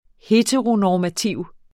Udtale [ ˈheːtʁonɒːmaˌtiwˀ ] eller [ ˈhetəʁonɒːmaˌtiwˀ ]